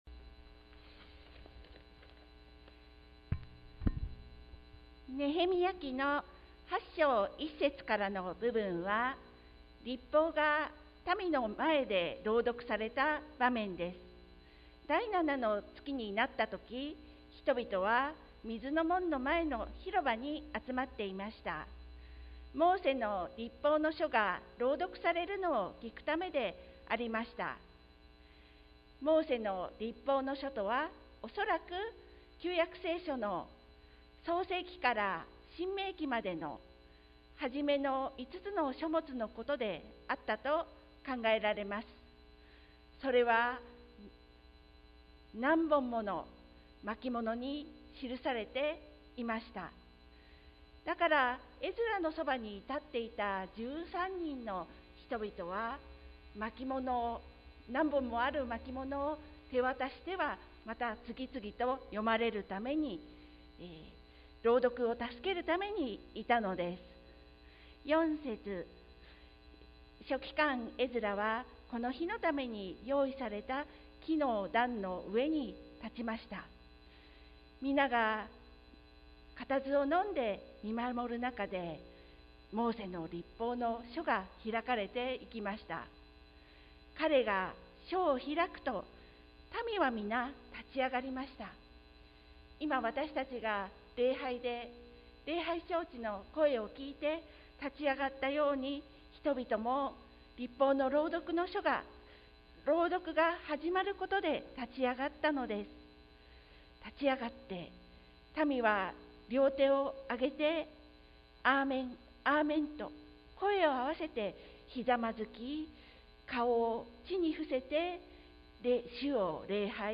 sermon-2021-01-31